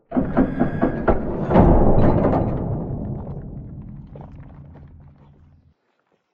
scpcb-godot/SFX/Door/EndroomDoor.ogg at 648f0d0106a2afa42baa5e048925b8eac28285e8
EndroomDoor.ogg